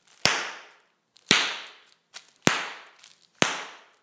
Pops.mp3